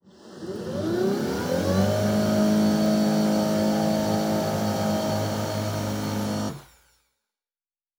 Servo Big 4_2.wav